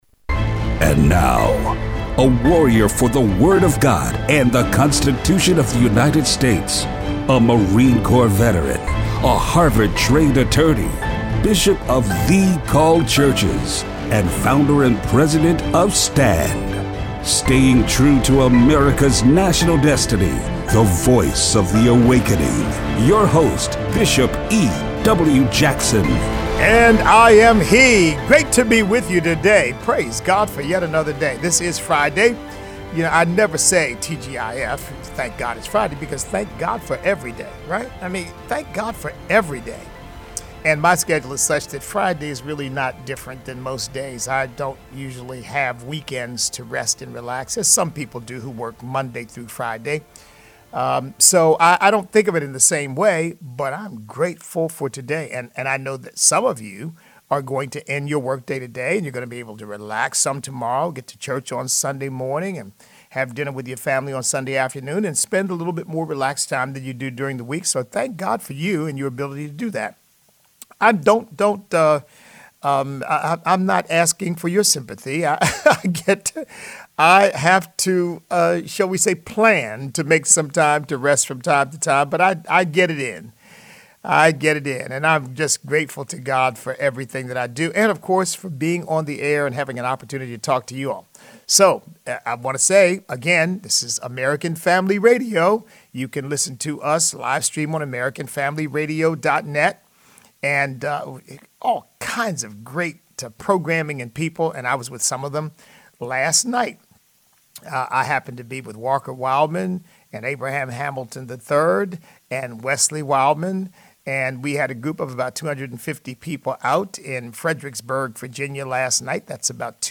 The cult-like behavior of America's Left. Listener call-in.